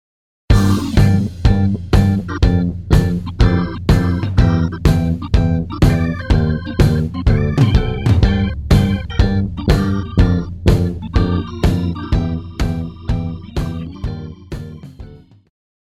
爵士,流行
電吉他
樂團
演奏曲
放克,節奏與藍調
獨奏與伴奏
有節拍器
Check out the horn chorale into the big finale!
Guitar 吉他
Trumpet 小號
Tenor Sax 次中音薩克斯
Trombone 長號
Keyboards 鍵盤
Bass 貝斯
Drums 鼓
Percussion 打擊樂器